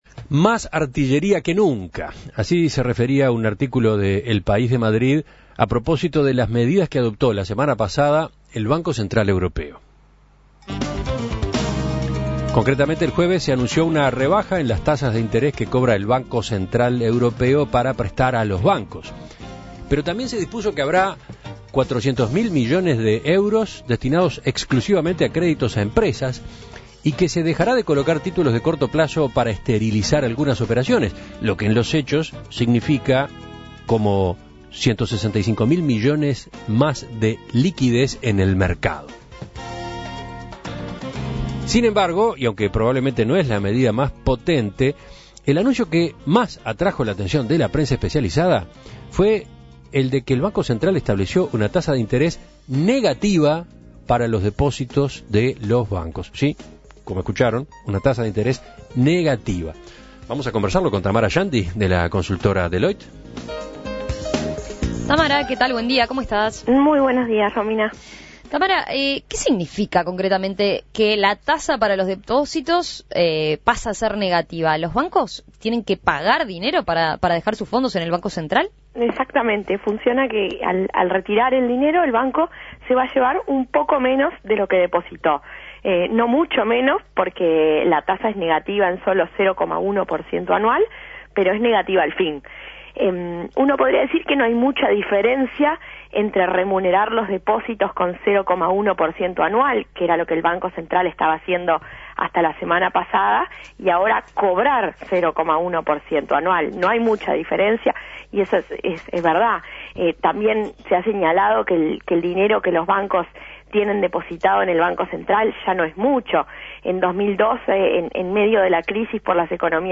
Análisis y perspectivas